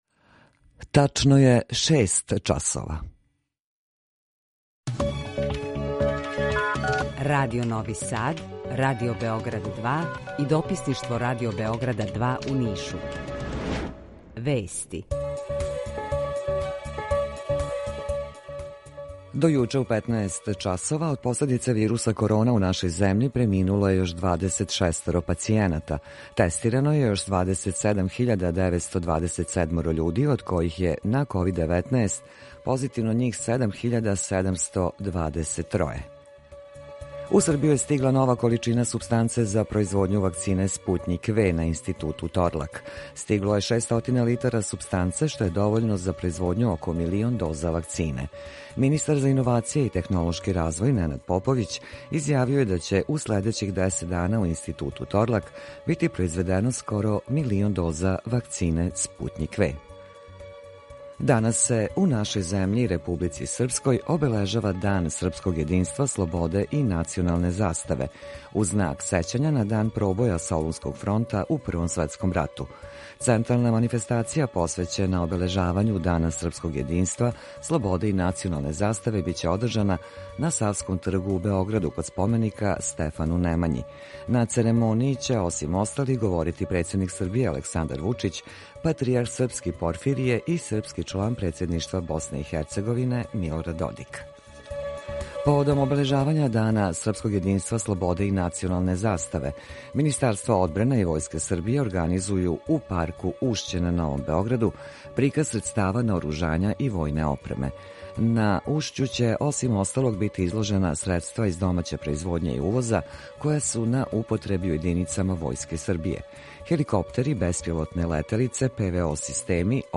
Укључење из Kосовске Митровице
Јутарњи програм из три студија
У два сата, ту је и добра музика, другачија у односу на остале радио-станице.